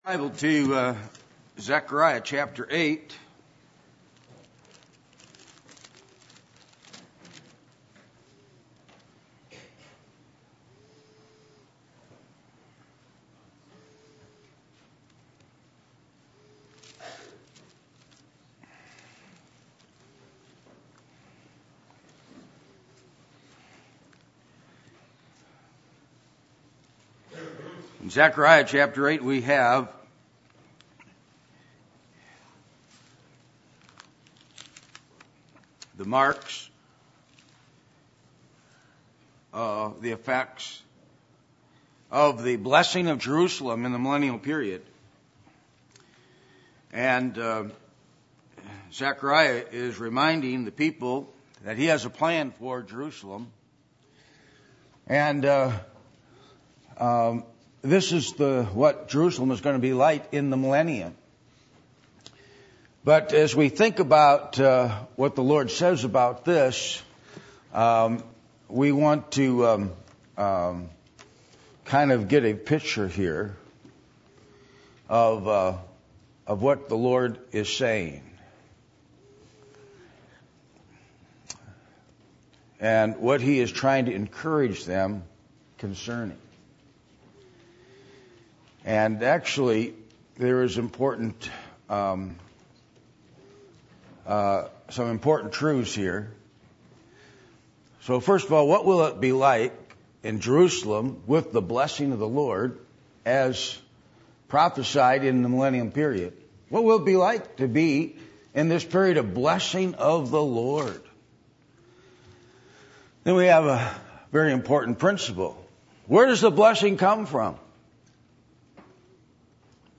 Passage: Zechariah 8:1-23 Service Type: Sunday Evening